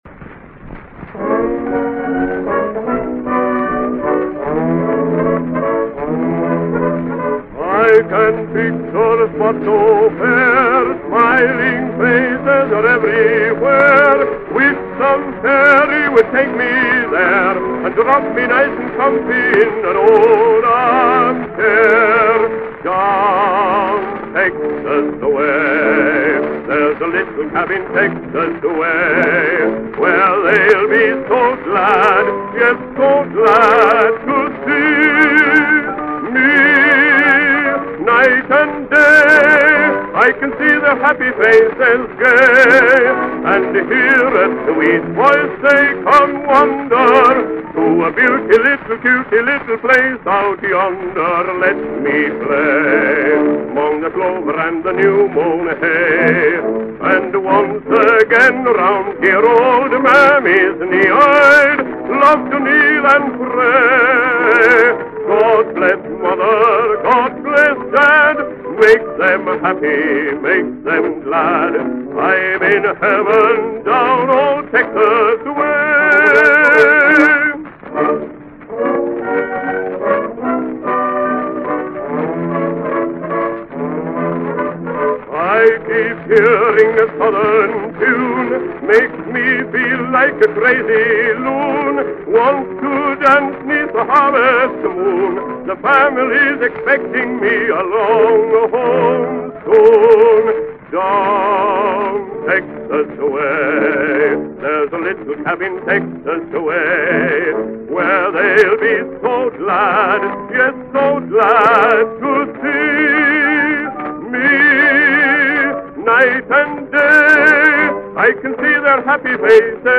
Canadian release